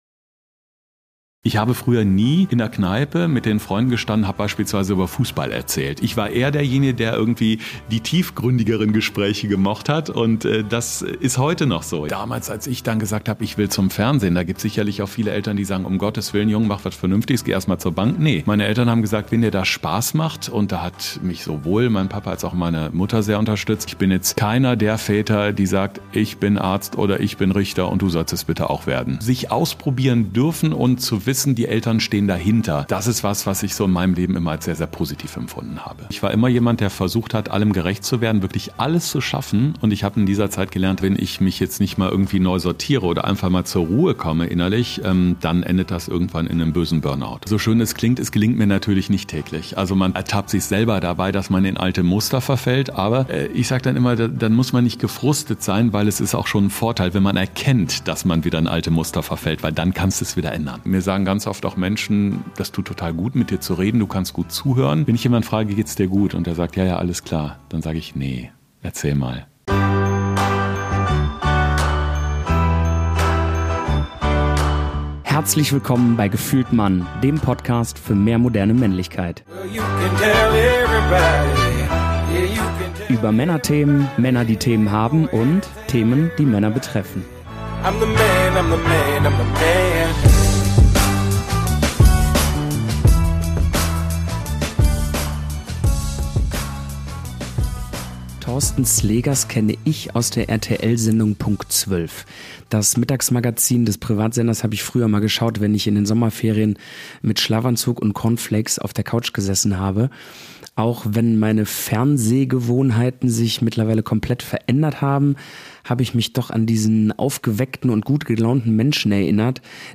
Also: Beste Voraussetzungen für ein entspanntes Gespräch.